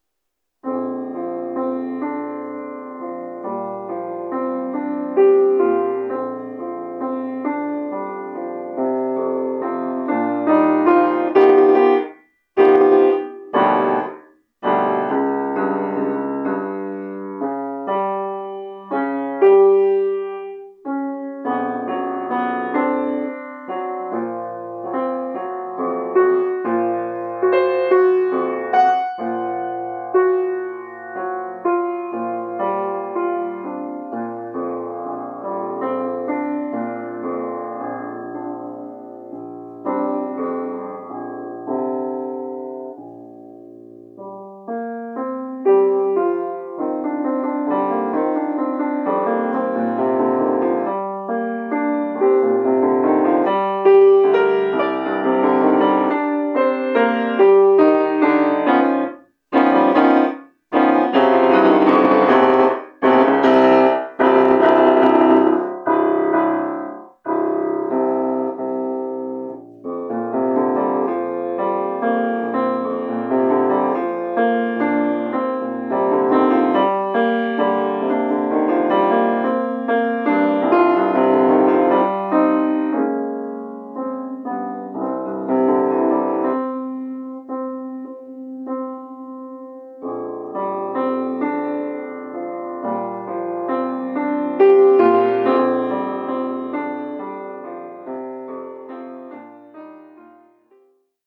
Oeuvre pour piano solo.